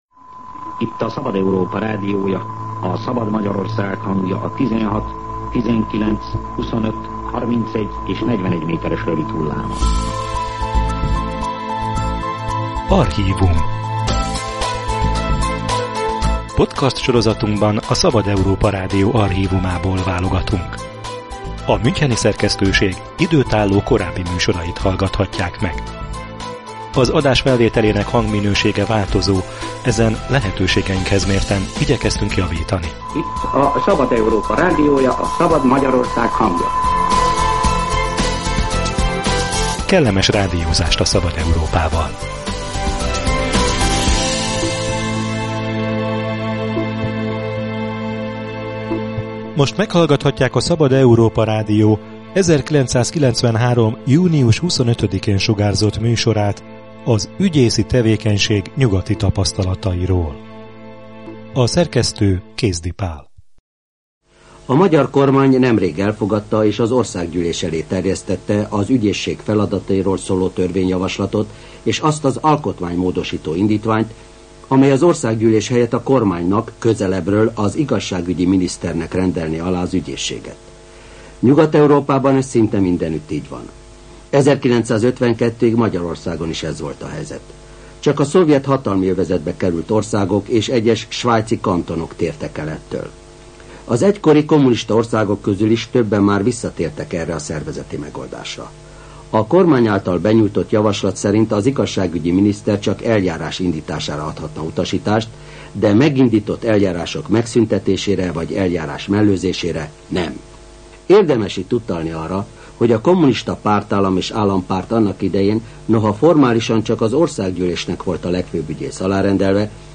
A kormány vagy a parlament felügyelje-e a Legfőbb Ügyészséget? – ezt kellett eldöntenie a kilencvenes évek elején a fiatal magyar demokráciának. A Szabad Európa Rádió 1993 júniusában sugárzott interjút Bócz Endrével, Budapest akkori főügyészével az ügyészségi szervezetek nyugati mintáiról.